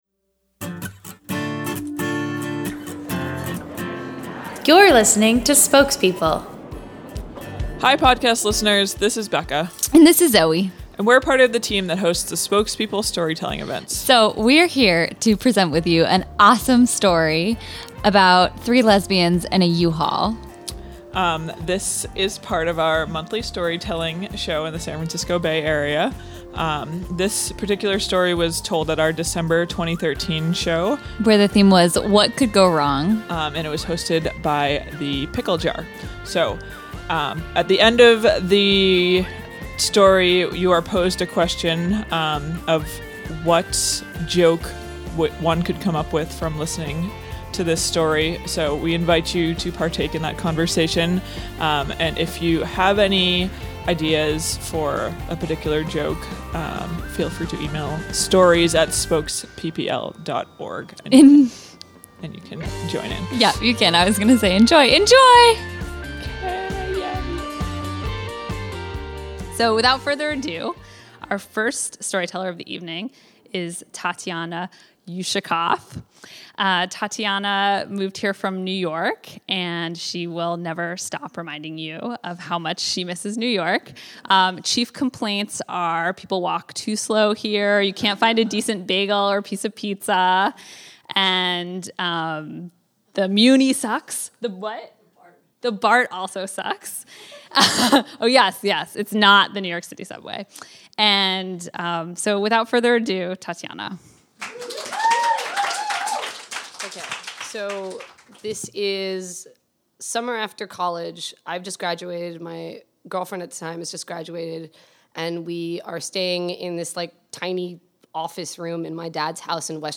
This story comes from our December 2013 show, “What Could Go Wrong?”